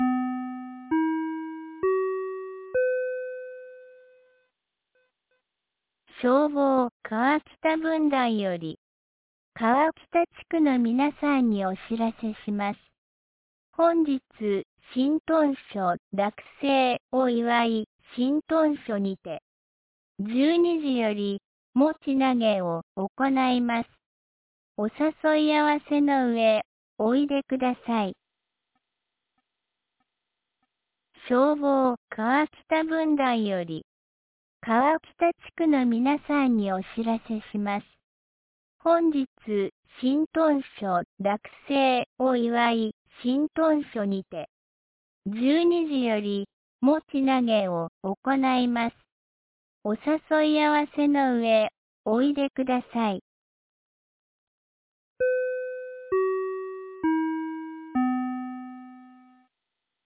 2025年04月06日 09時01分に、安芸市より川北、江川へ放送がありました。